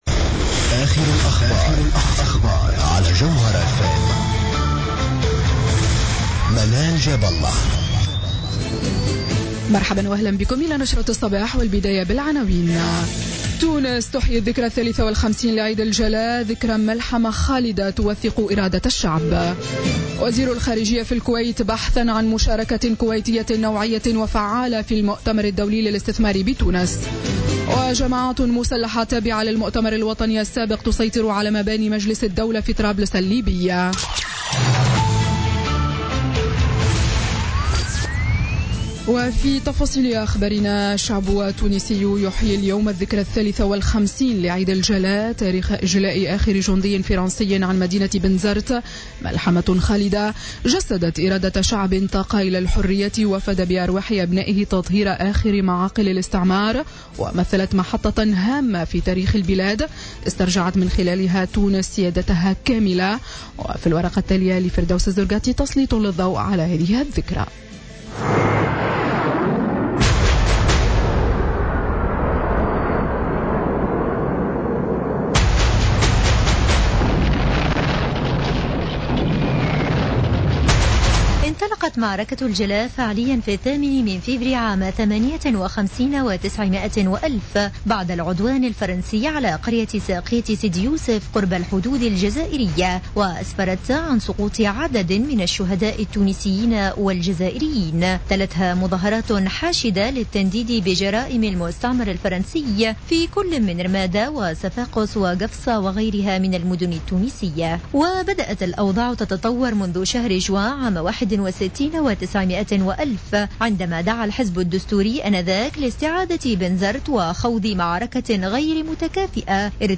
نشرة أخبار السابعة صباحا ليوم السبت 15 أكتوبر 2016